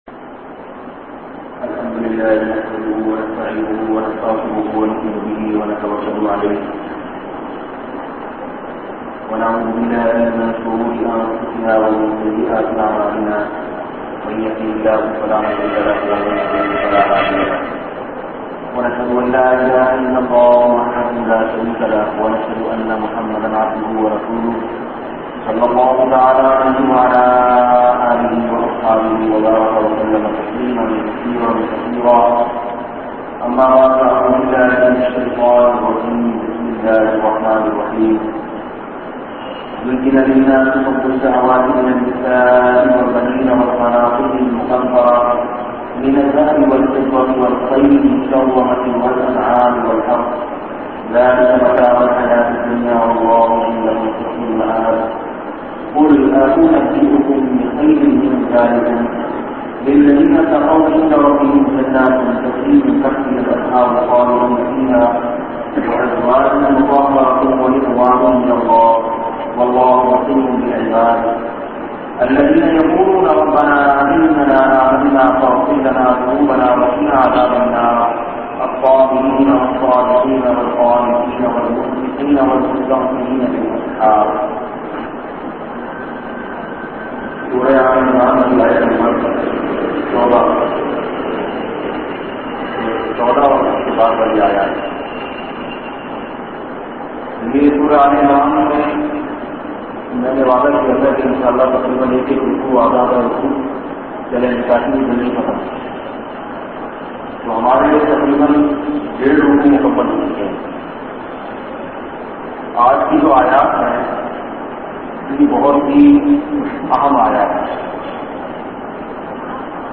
Jald Shadee Karnay Kay Faeiday bayan mp3